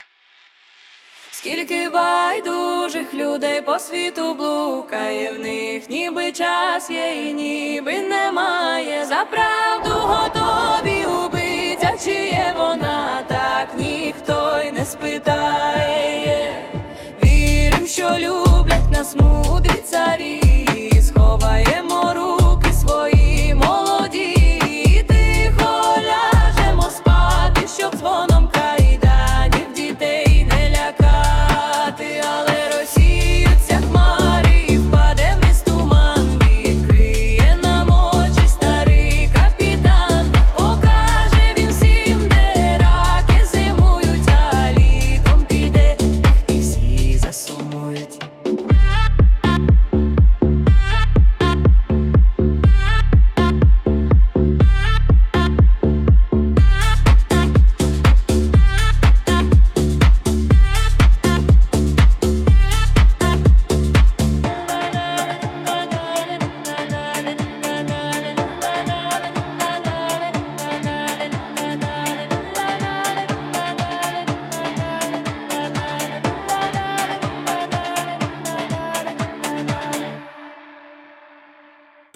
Мандрівник (+ 🎧музична версія)
СТИЛЬОВІ ЖАНРИ: Ліричний